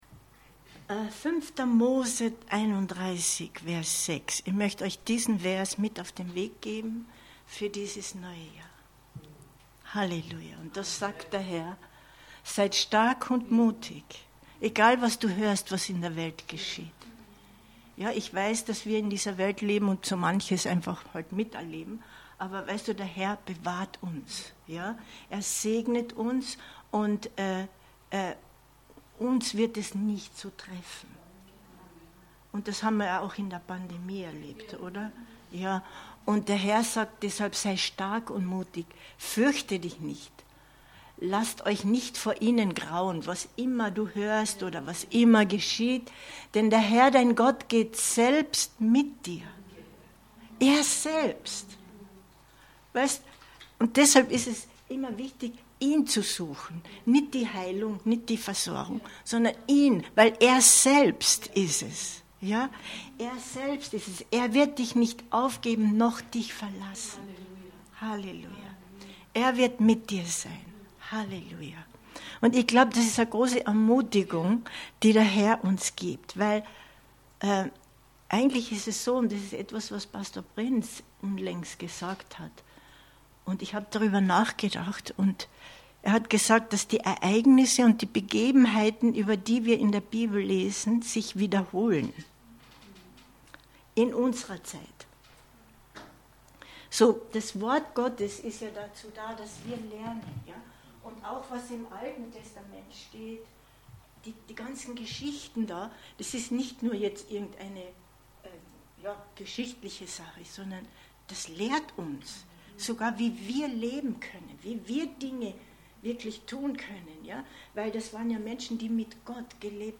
Du bist gesegnet um ein Segen zu sein 01.01.2023 Predigt herunterladen